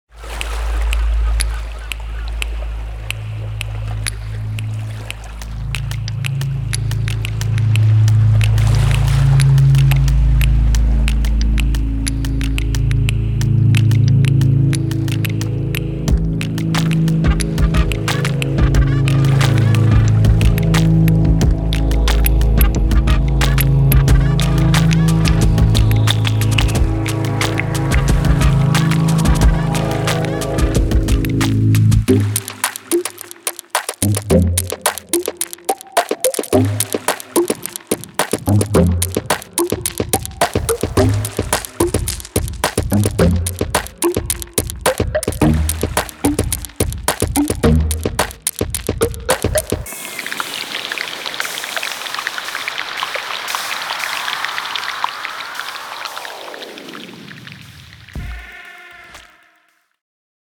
Field Recordings